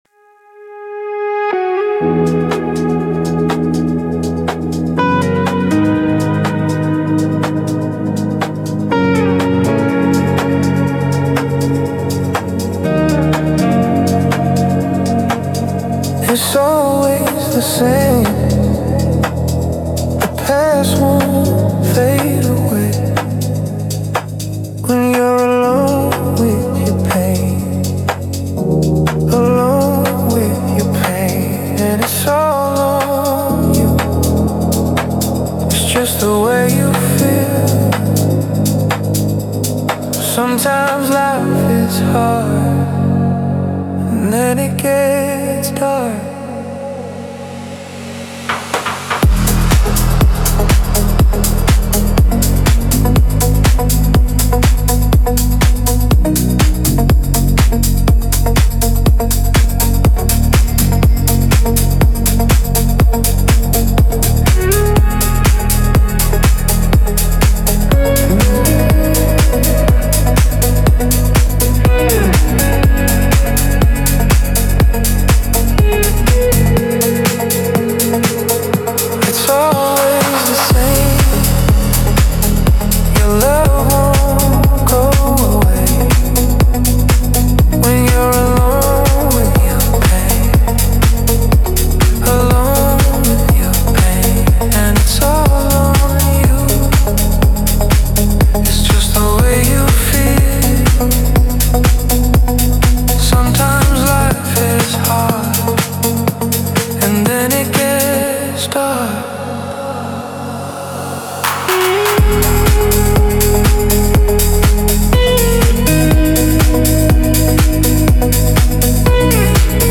Трек размещён в разделе Зарубежная музыка / Танцевальная.